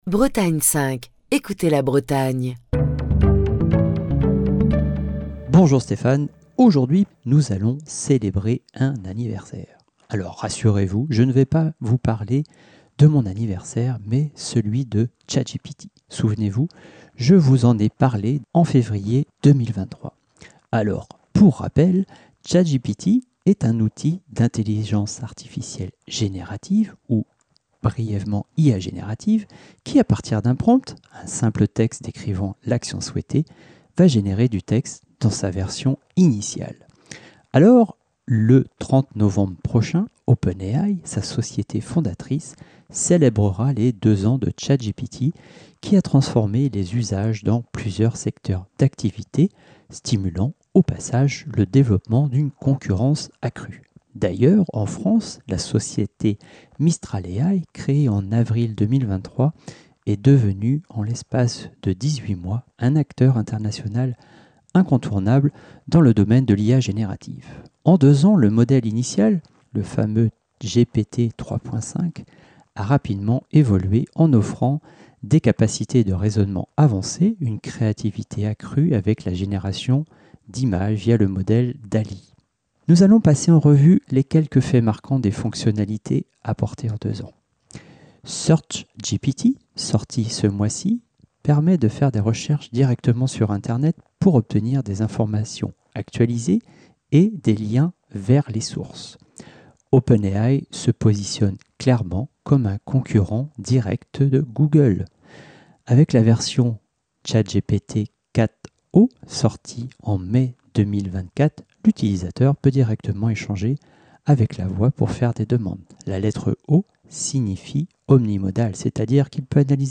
Chronique du 27 novembre 2024.